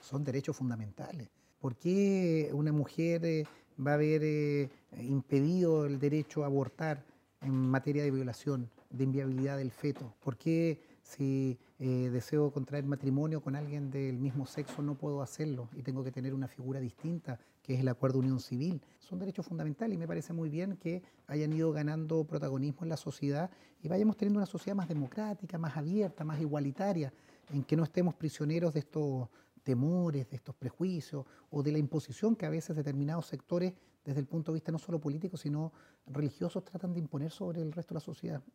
Escuche al senador De Urresti por derechos fundamentales